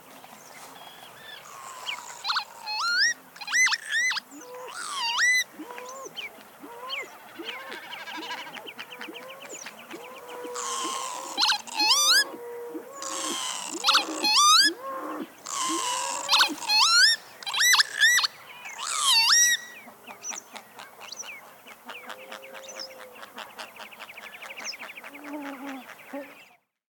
На этой странице собраны звуки чибиса — звонкие и мелодичные крики этой птицы.
Голос чибиса в дикой природе